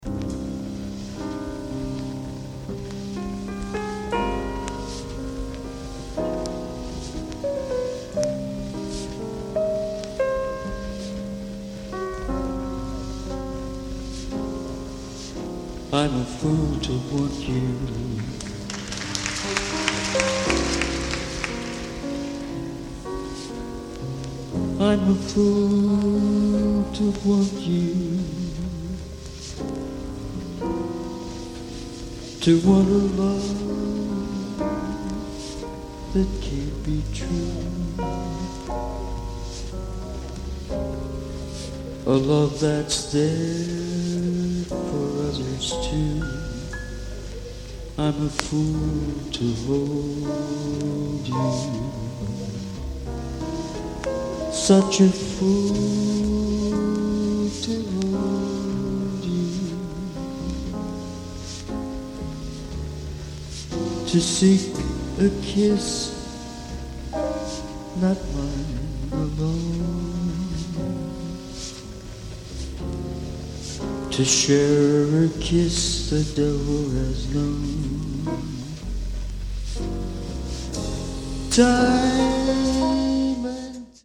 Recorded live at Hitomi-Kinen Kodo, Tokyo, June 14, 1987.